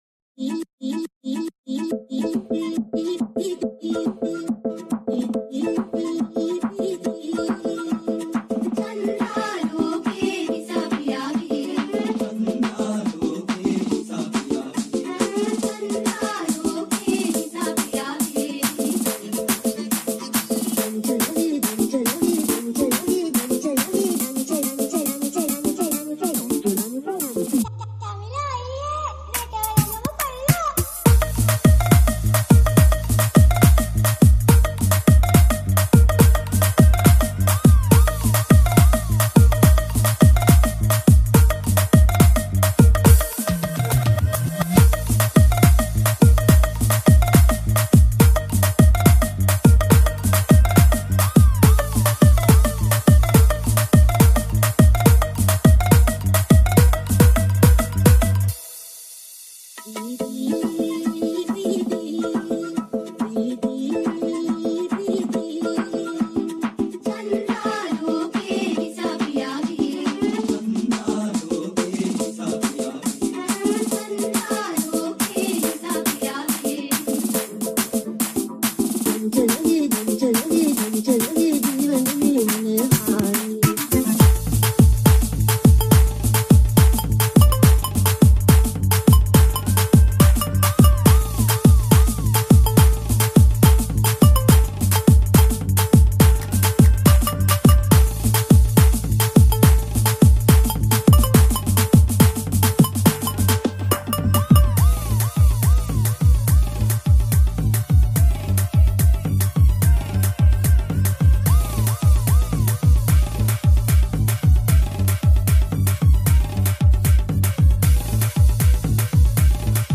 High quality Sri Lankan remix MP3 (2.1).